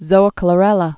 (zōə-klə-rĕlə)